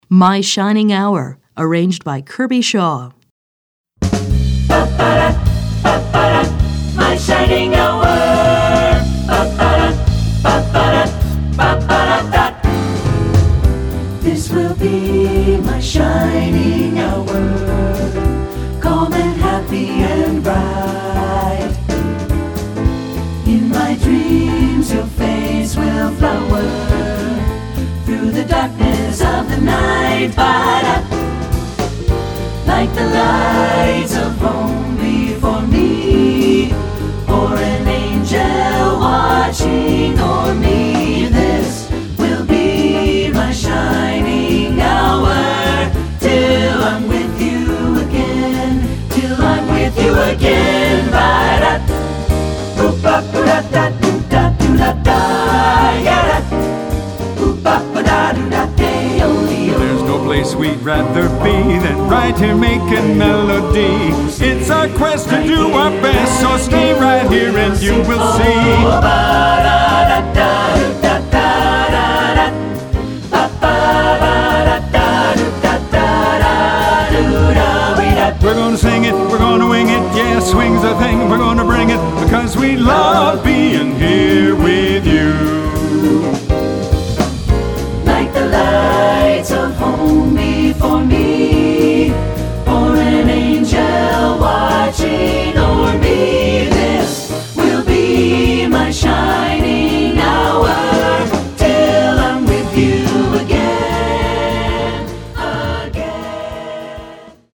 Choral Jazz Movie/TV/Broadway